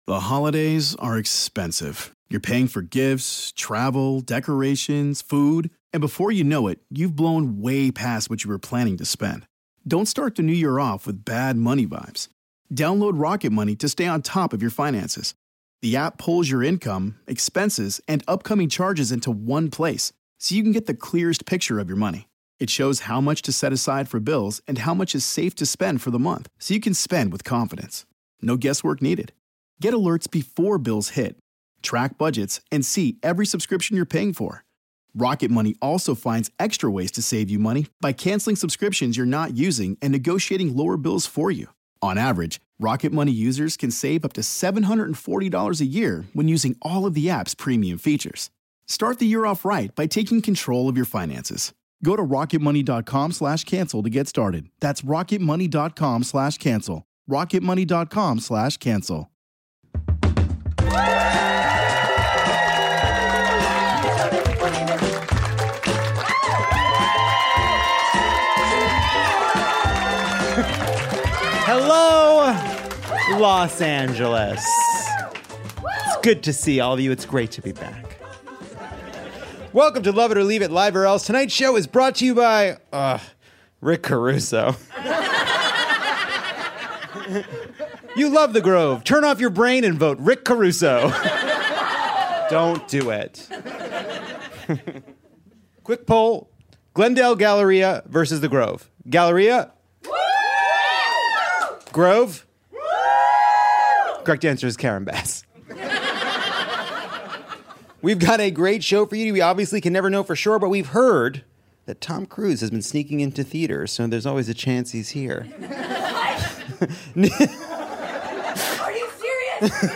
Lovett or Leave It returns to Dynasty Typewriter as we choose to be mad after a sad week. Comedian Nish Kumar busts up Partygate, while author and actor Ryan O’Connell calls ‘em like he sees ‘em.